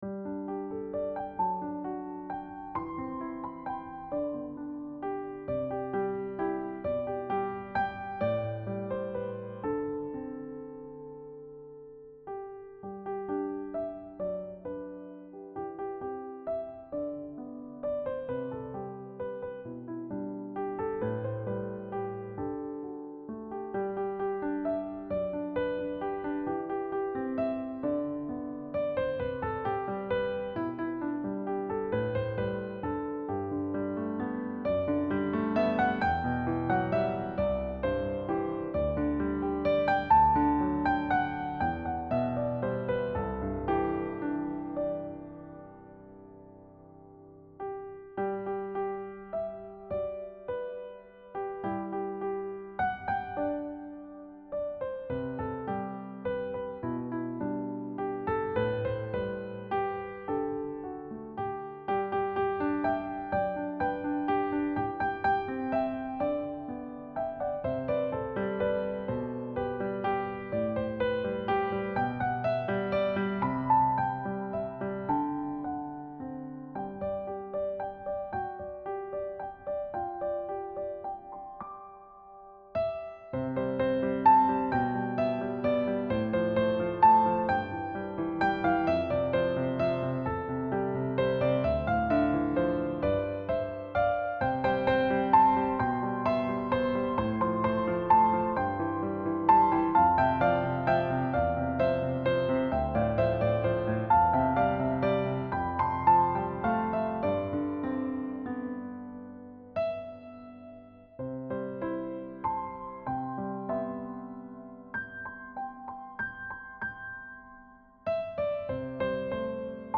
Key: G Major (traditional carol setting)
Time signature: 3/4 (dance-like feel)